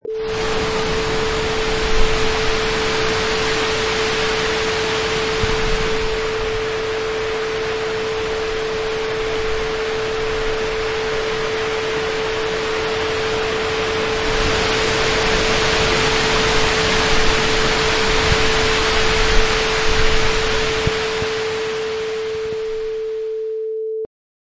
Wav: Wind in Trees 2
Excellent recording of wind blowing and rustling through trees before a storm
Product Info: 48k 24bit Stereo
Category: Nature / Weather - Wind
Try preview above (pink tone added for copyright).
Wind_in_Trees_2.mp3